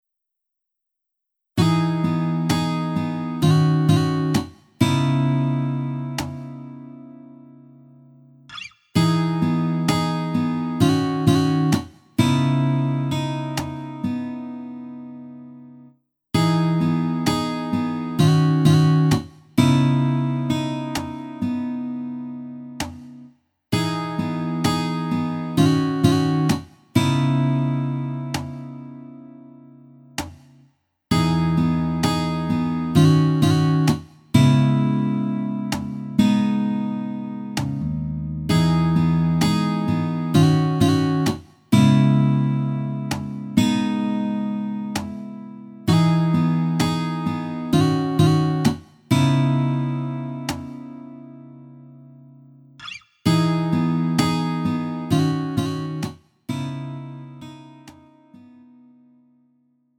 음정 -1키 2:21
장르 구분 Lite MR